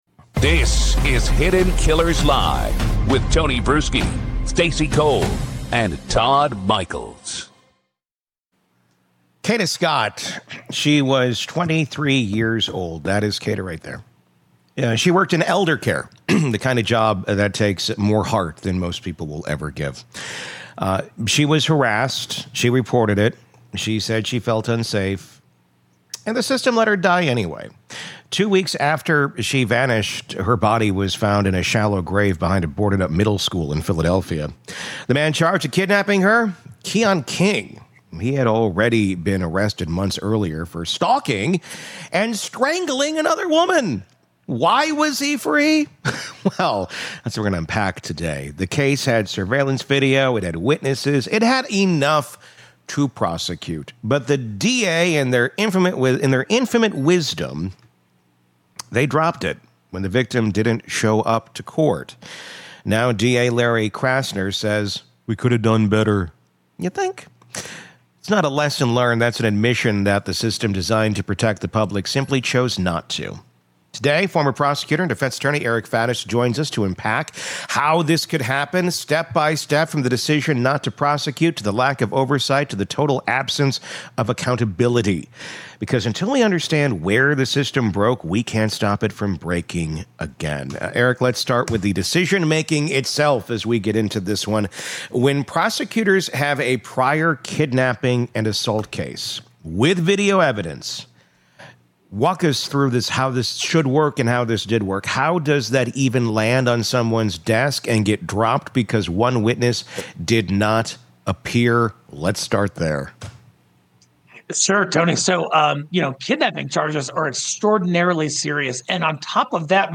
From one preventable death to a pattern of repeat-offender releases, this conversation exposes the difference between justice reform — and simply failing to prosecute.